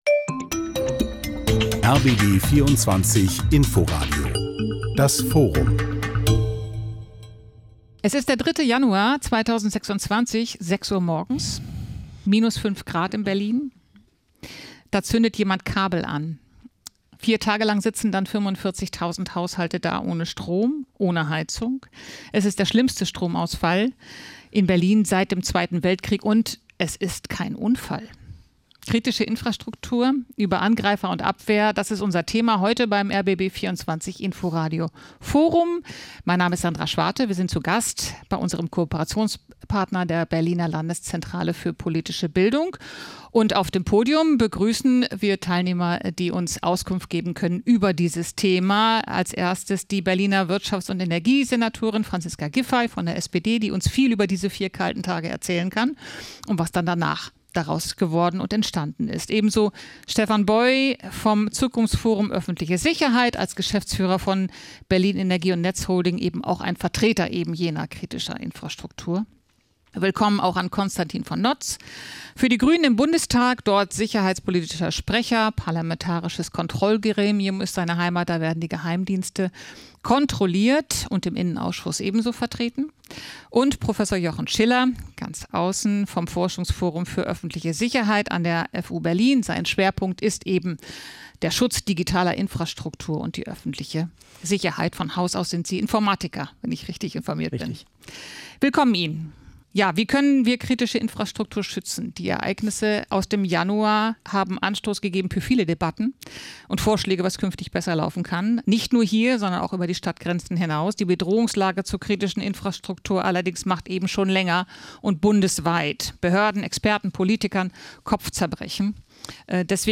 Spannende Gespräche und Diskussionsrunden